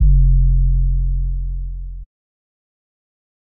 SouthSide Kick (26).wav